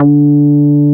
P MOOG D4P.wav